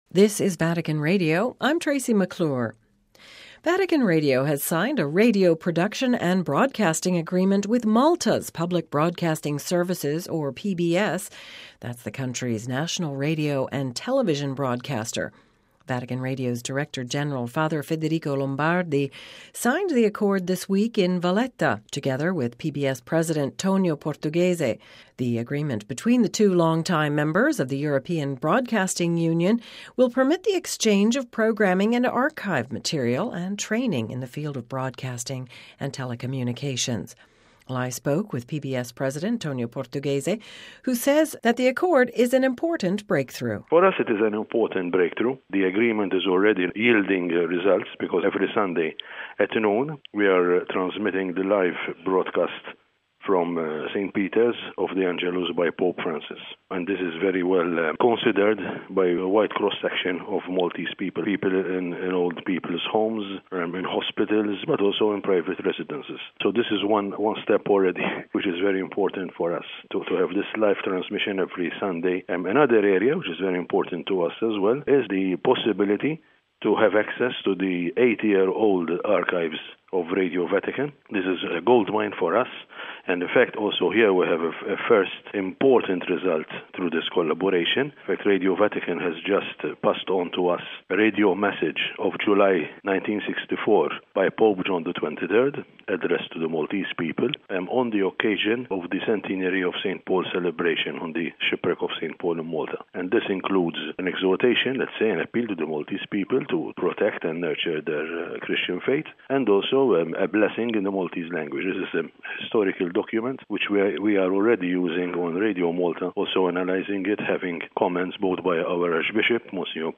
brief interview